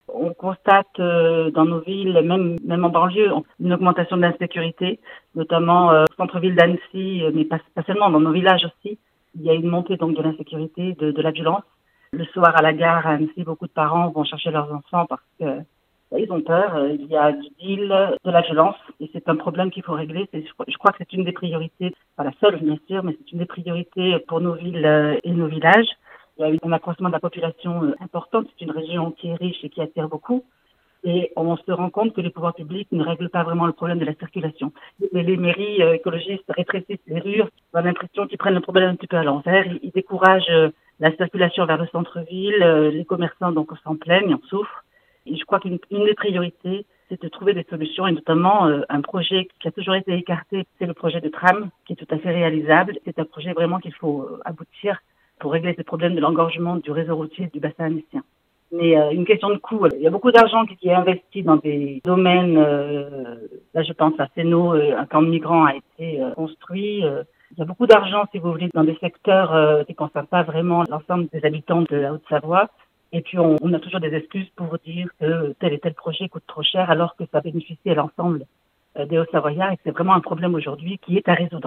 Voic les interviews des 8 candidats de cette 2eme circonscription de Haute-Savoie (par ordre du tirage officiel de la Préfecture) :